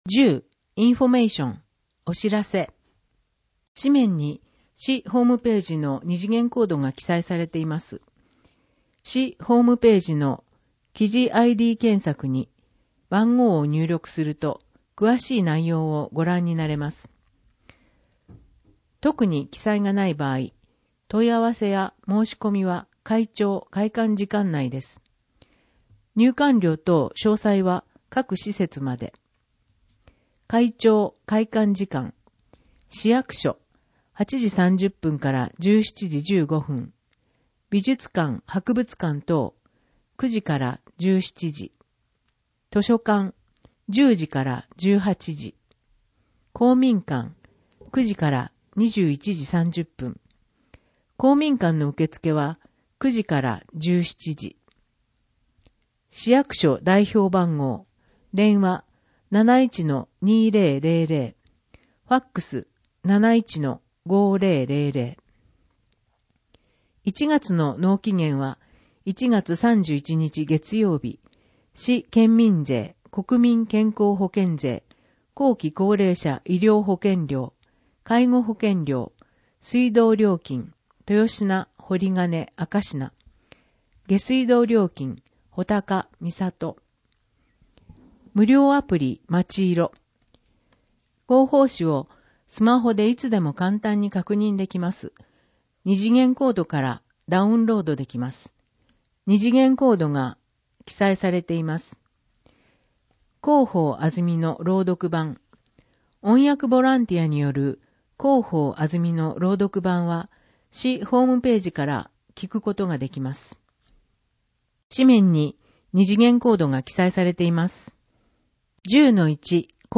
広報あづみの朗読版340号（令和4年1月19日発行号) - 安曇野市公式ホームページ
「広報あづみの」を音声でご利用いただけます。この録音図書は、安曇野市中央図書館が制作しています。